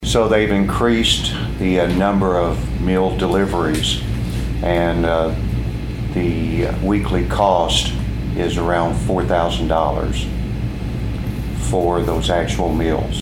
County Auditor Louie Sieberlich says the weekly cost is into the thousands.